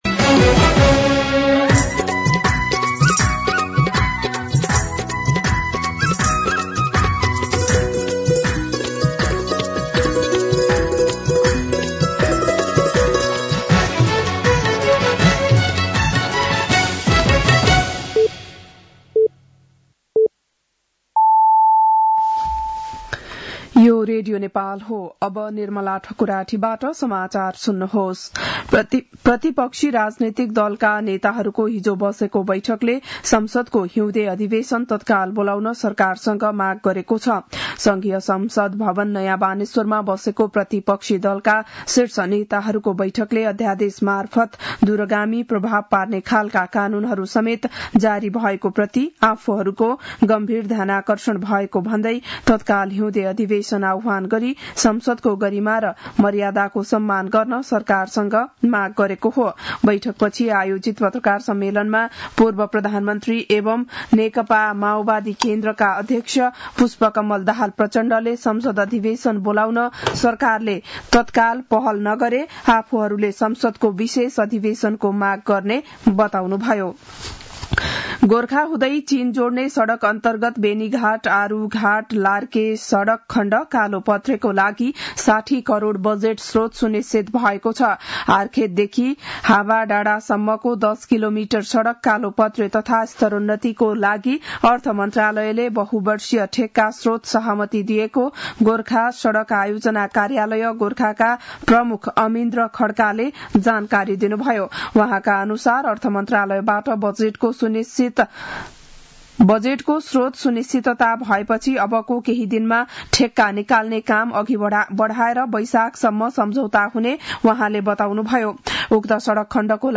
बिहान ११ बजेको नेपाली समाचार : ६ माघ , २०८१
11-am-Nepali-News-1.mp3